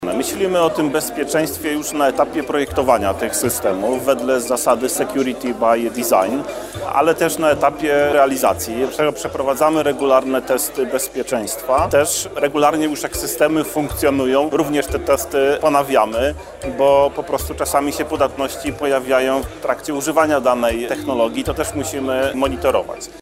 Wiceprezes ZUS Sławomir Wasielewski podkreślił podczas Forum Ekonomicznego w Karpaczu, że bezpieczeństwo należy uwzględniać już na etapie projektowania systemów.